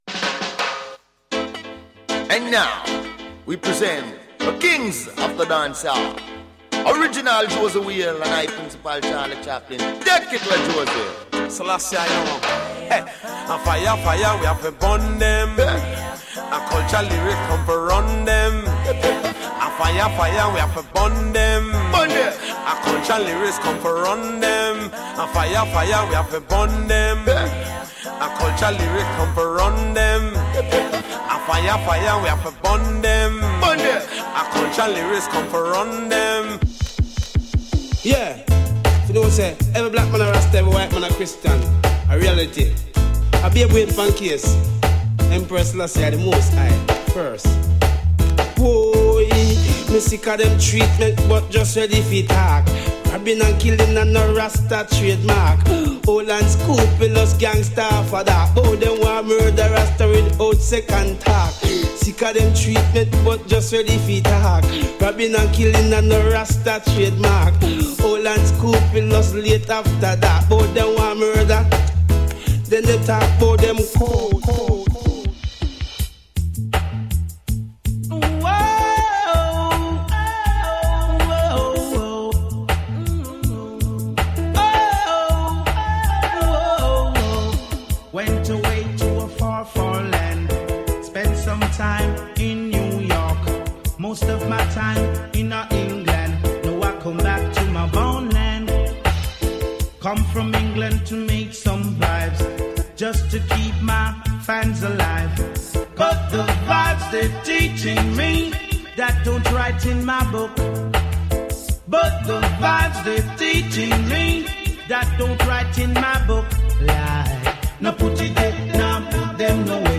A tasty Ragga podcast